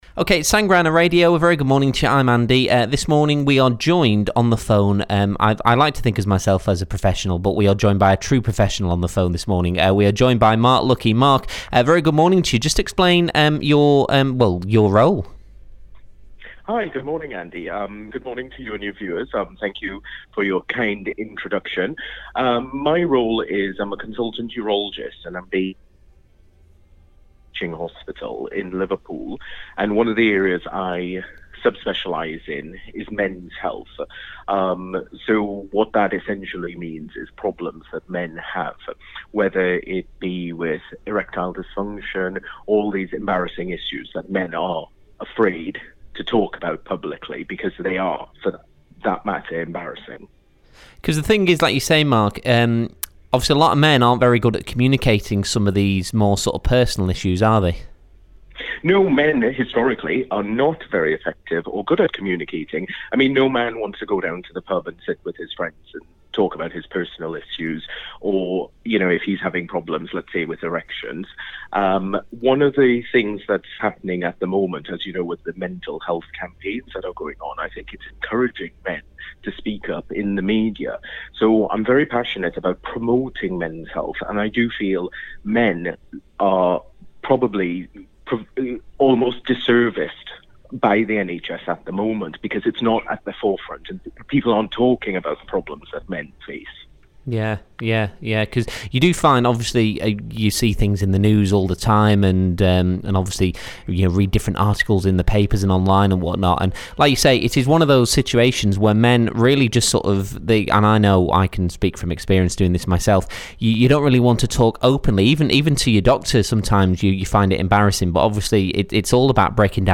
Sefton Suite Interview.mp3